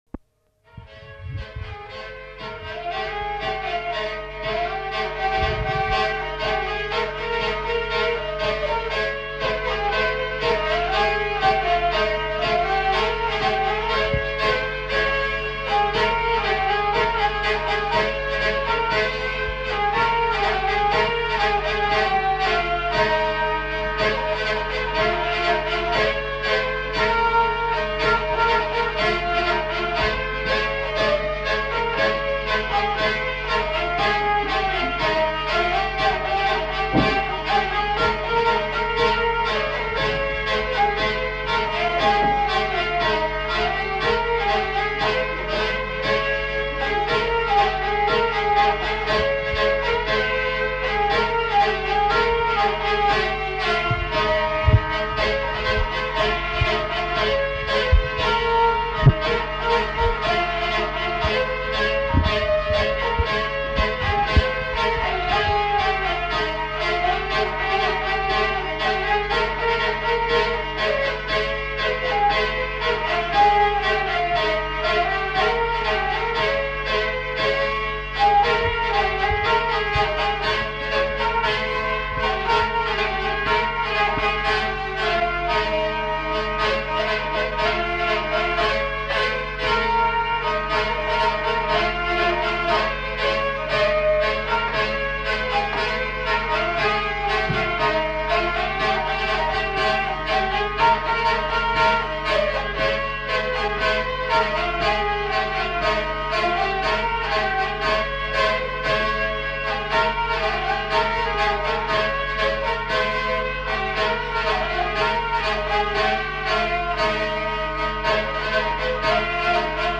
Aire culturelle : Gabardan
Lieu : Mauléon-d'Armagnac
Genre : morceau instrumental
Instrument de musique : vielle à roue
Danse : samba